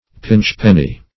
Pinchpenny \Pinch"pen`ny\, n. A miserly person.